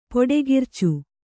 ♪ poḍegircu